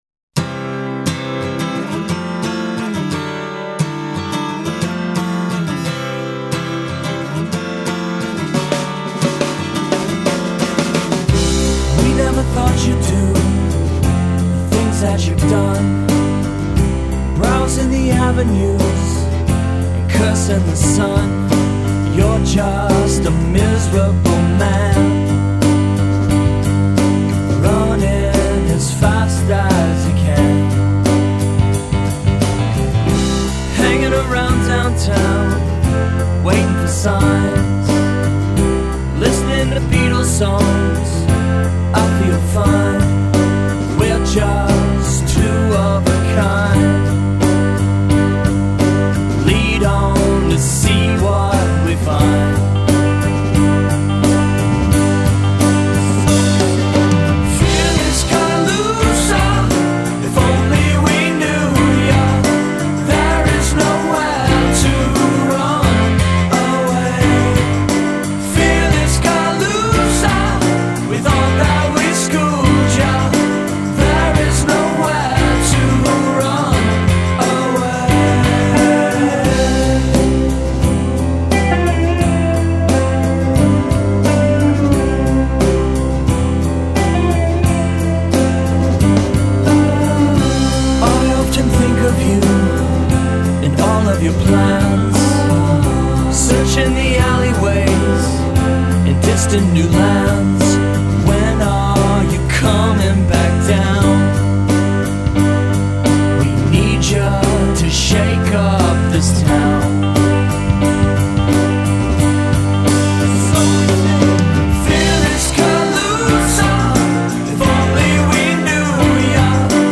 Vocals, Guitar
Drums
Trumpet